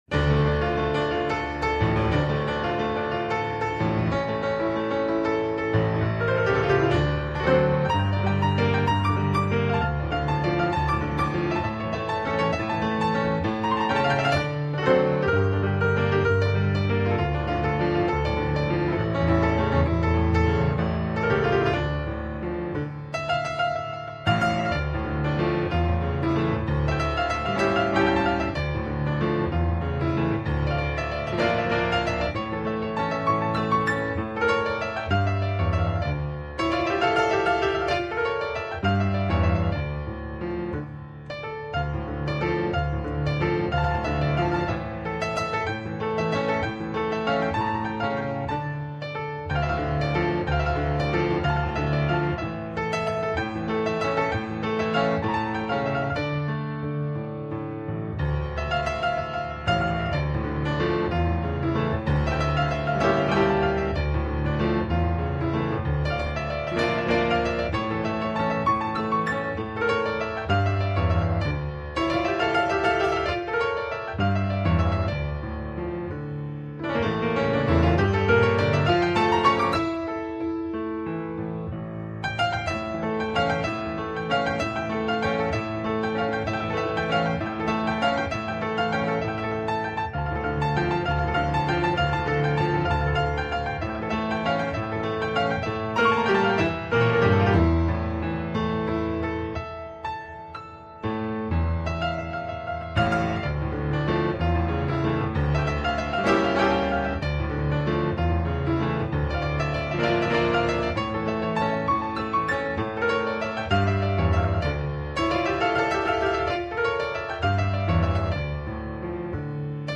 ♫♫ بی کلام